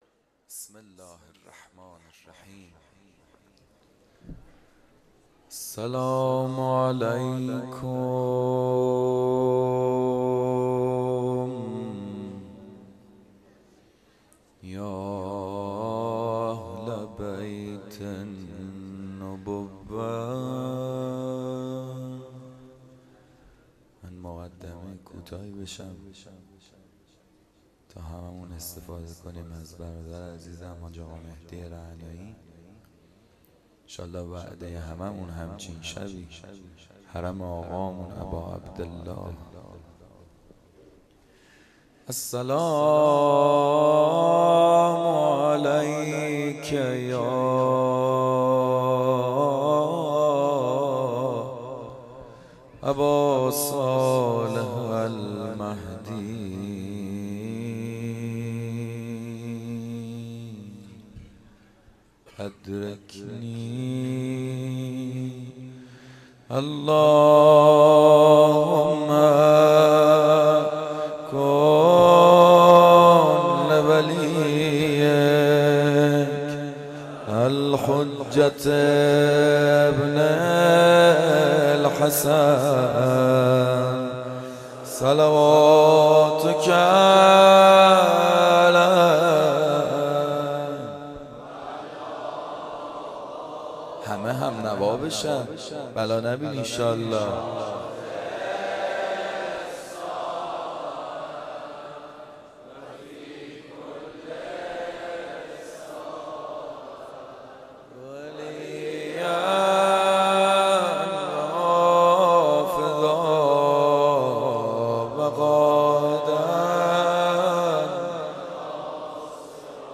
روضه
قالب : روضه